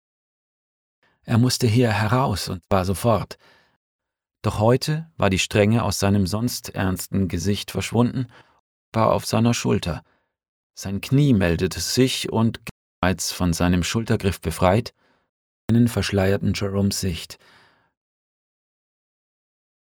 Unregelmäßige Lautstärkeschwankungen
Hier Screenshots der Presets und eine Aufnahme mit den Fehlern.